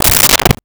Car Door Close 01
Car Door Close 01.wav